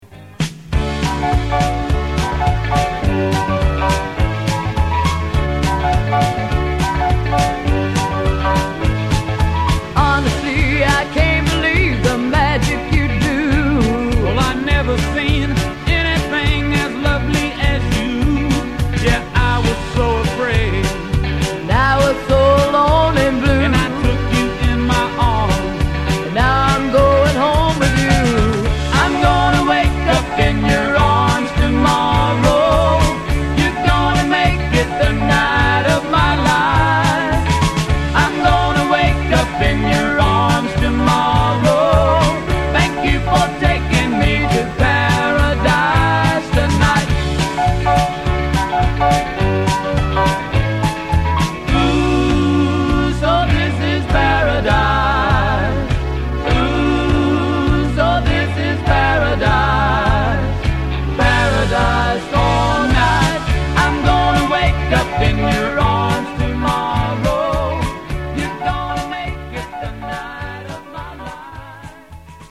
Country Stuff